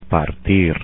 Click on the colored ball to hear the infinitive.